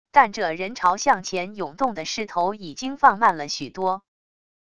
但这人潮向前涌动的势头已经放慢了许多wav音频生成系统WAV Audio Player